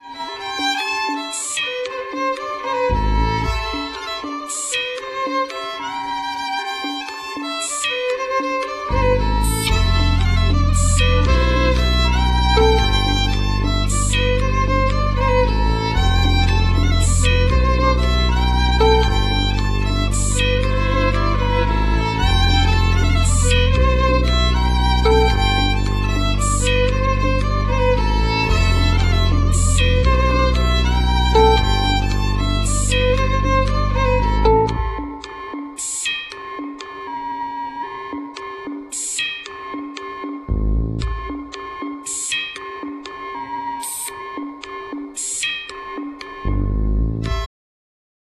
skrzypce / fiddle
marimba, moog
perkusja / drums, moog